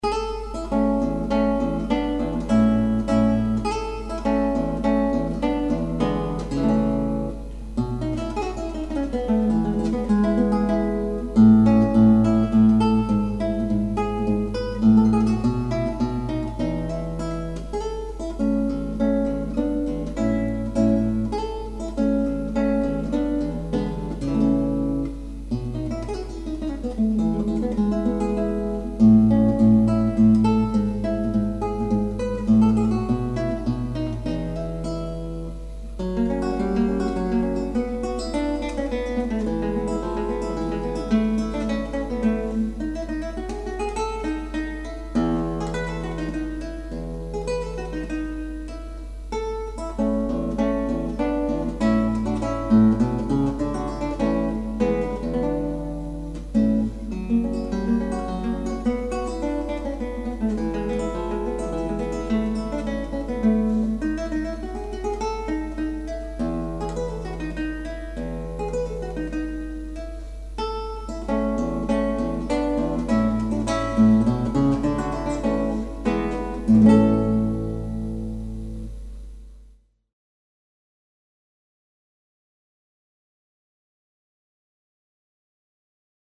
Audios Clásicos